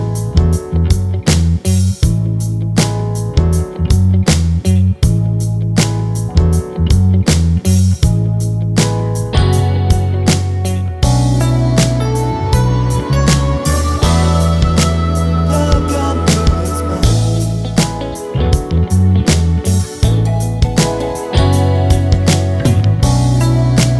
no Backing Vocals Duets 3:52 Buy £1.50